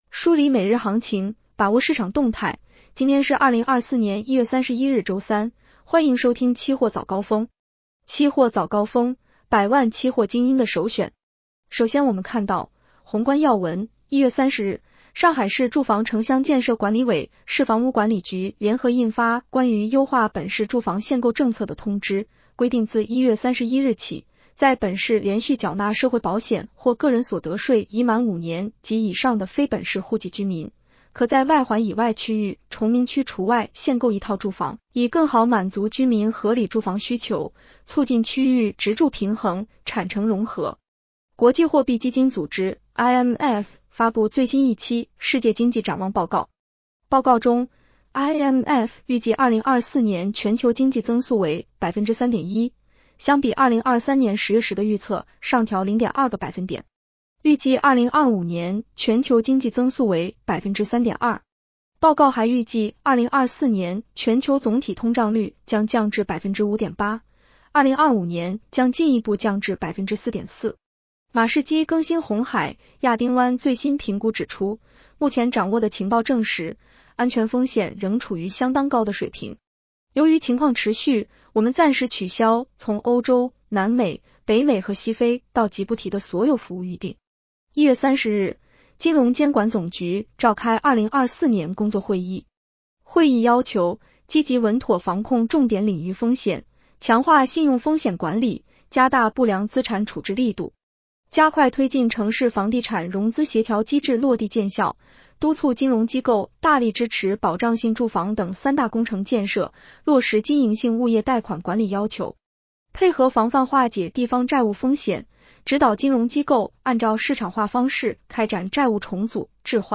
期货早高峰-音频版 女声普通话版 下载mp3 宏观要闻 1. 1月30日，上海市住房城乡建设管理委、市房屋管理局联合印发《关于优化本市住房限购政策的通知》，规定自1月31日起，在本市连续缴纳社会保险或个人所得税已满5年及以上的非本市户籍居民，可在外环以外区域（崇明区除外）限购1套住房，以更好满足居民合理住房需求，促进区域职住平衡、产城融合。